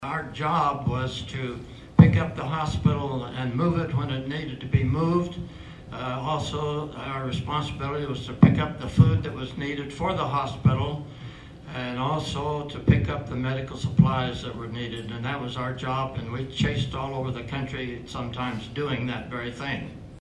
Stories were shared during a panel discussion Saturday at the American Legion.